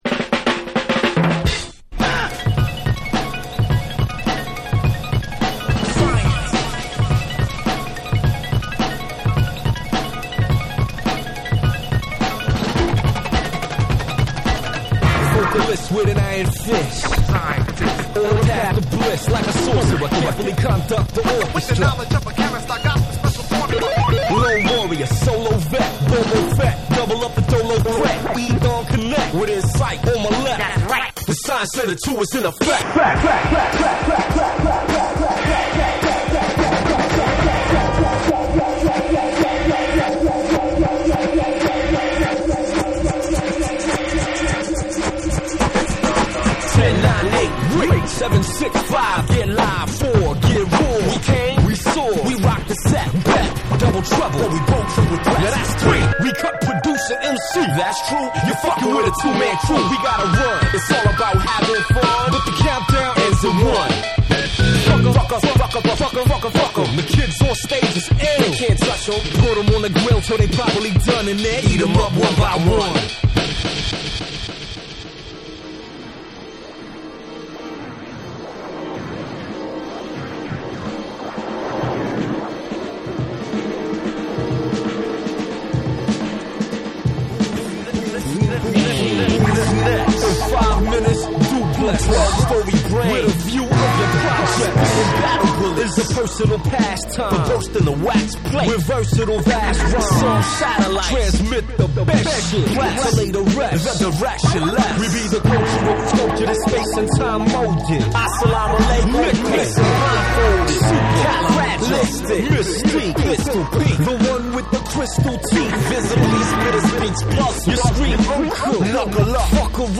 BREAKBEATS / HIP HOP